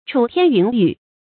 楚天云雨 注音： ㄔㄨˇ ㄊㄧㄢ ㄧㄨㄣˊ ㄧㄩˇ 讀音讀法： 意思解釋： 指巫山神女，或男女歡情 出處典故： 戰國 楚 宋玉《高唐賦》：「妾在巫山之陽，高丘之阻，旦為朝云，暮為行雨。」